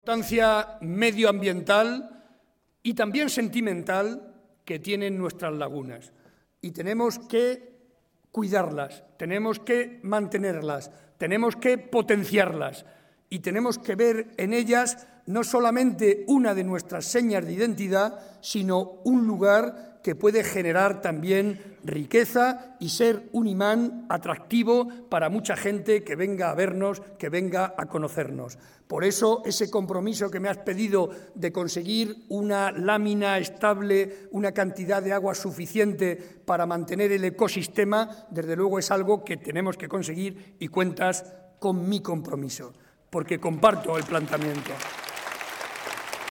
El presidente de Castilla-La Mancha y candidato a la reelección, José María Barreda, quiso comenzar su intervención en Villafranca de los Caballeros (Toledo) comprometiéndose a abrir la residencia para mayores de la localidad «en cuanto pase el día 22».